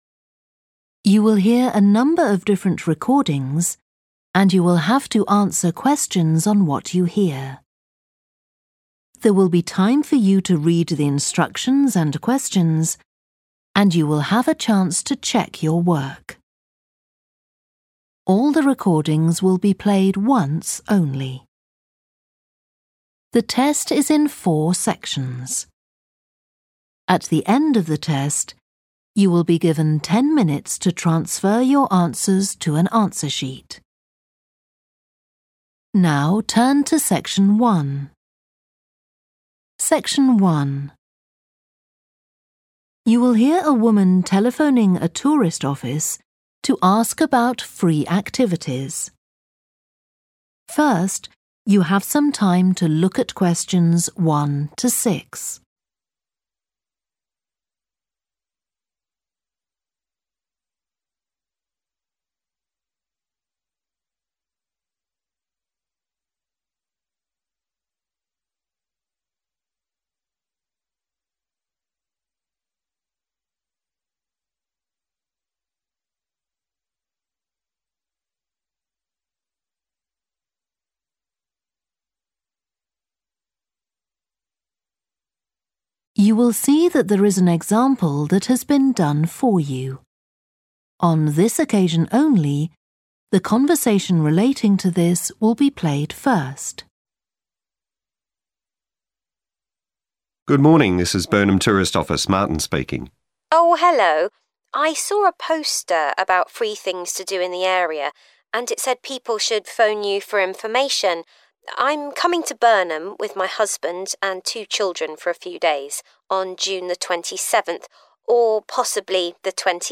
The caller wants to find out about events on